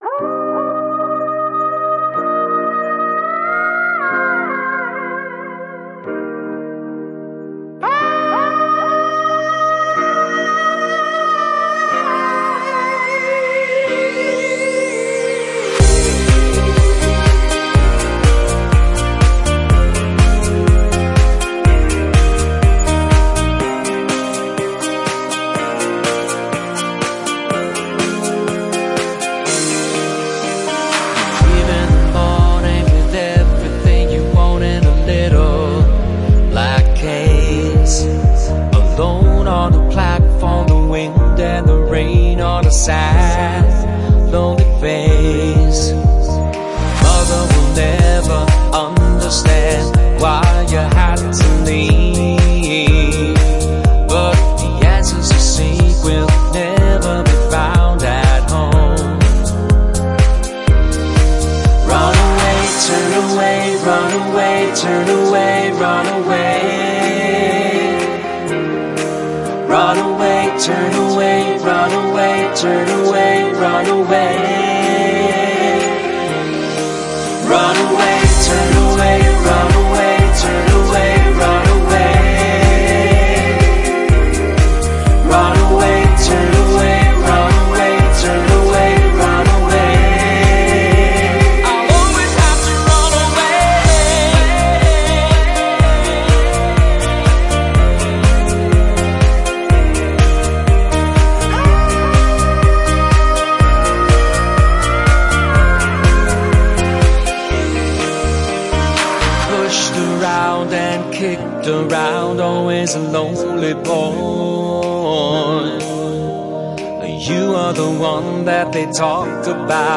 Keyboard
Drums and Sampler
Vocals